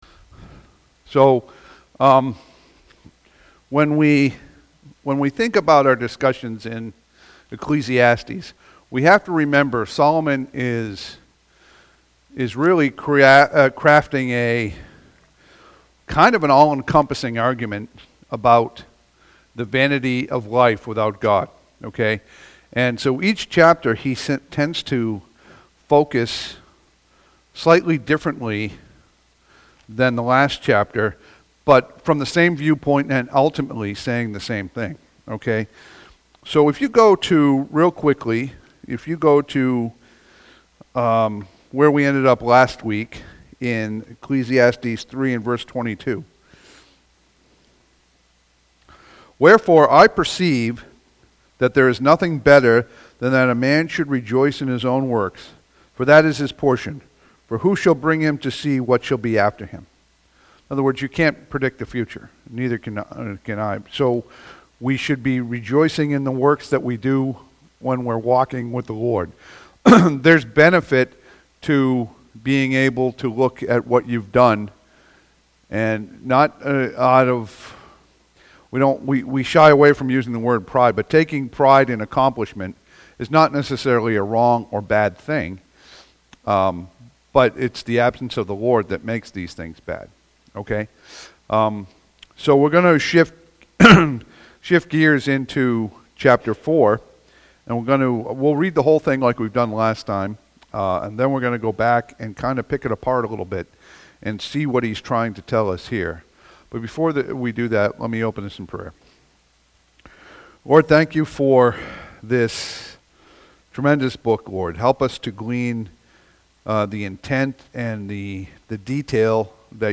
Passage: Ecclesiastes 4 Service Type: Sunday PM « June 22